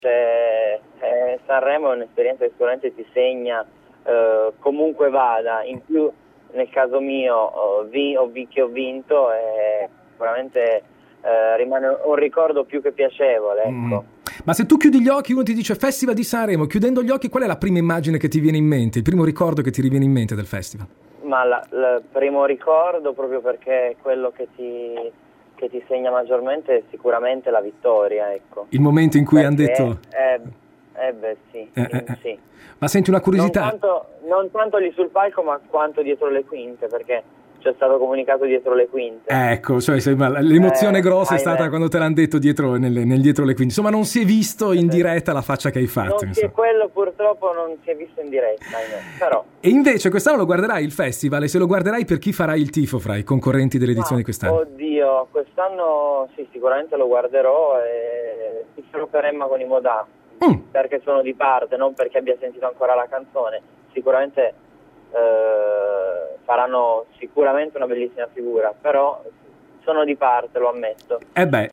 Valerio Scanu farà il tifo per Emma e il Modà per il Festival di Sanremo. Lo ha raccontato nell’intervista rilasciata oggi a Radio Onda Ligure 101, nella quale ha ricordato le emozioni vissute lo scorso anno sul palco dell’Ariston.